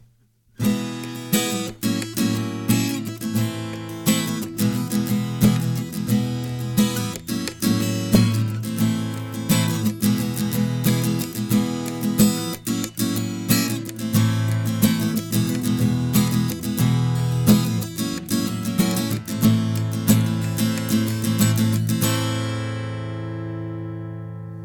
It's got some compression, but no other effects: both tracks are with a MC-012 using a modded MOTU pre (Black Lion Audio modified Traveler)